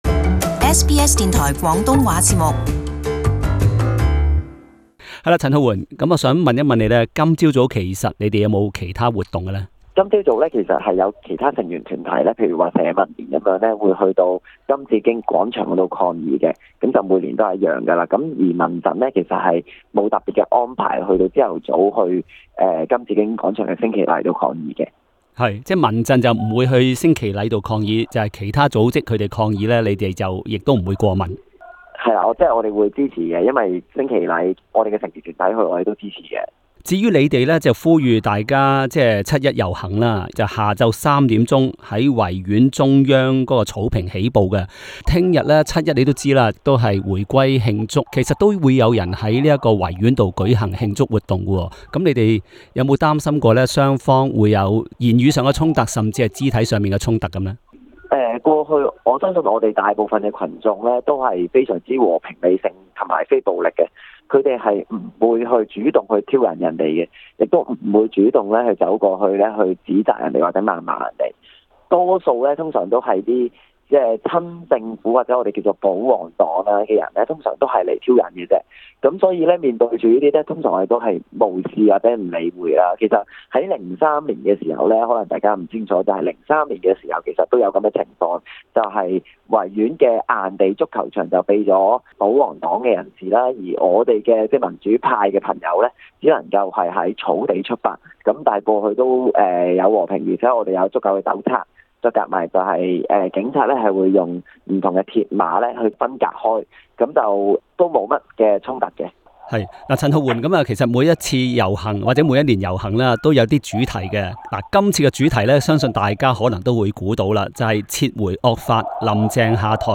請聼這節時事報導